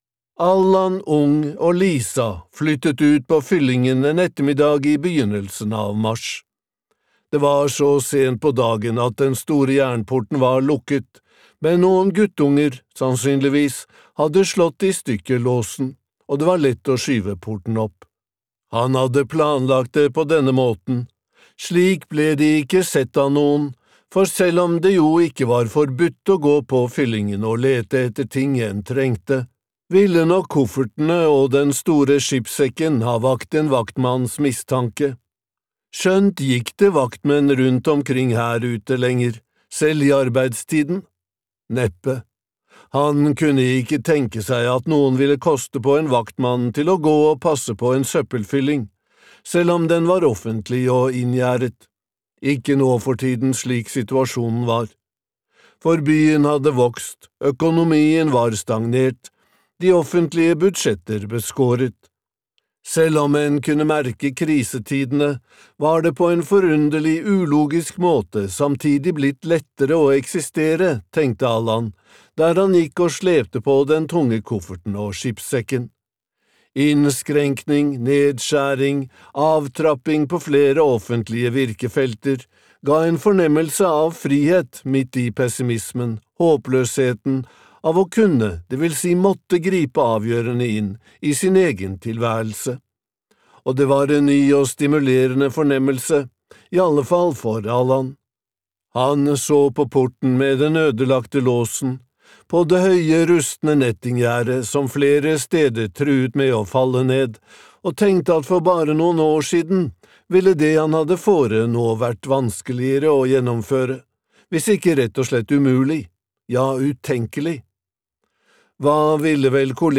Uår - aftenlandet (lydbok) av Knut Faldbakken